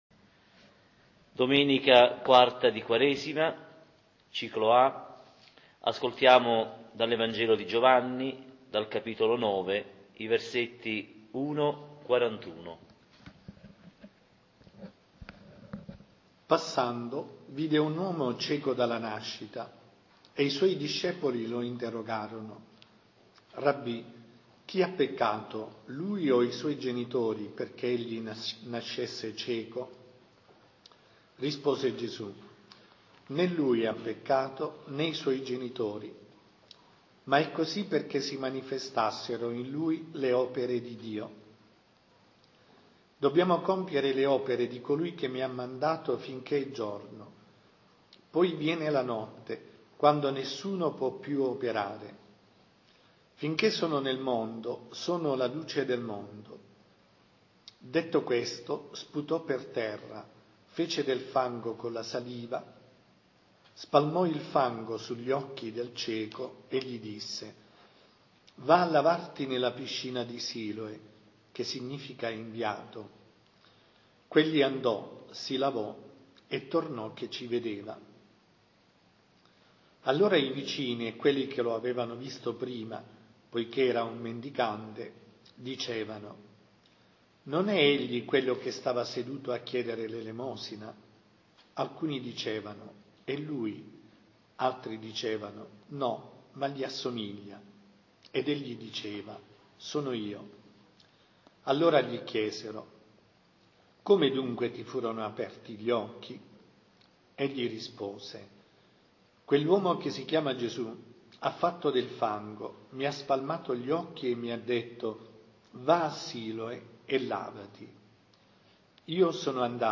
Lectio divina della Domenica «DEL CIECO NATO», IV di Quaresima, Anno A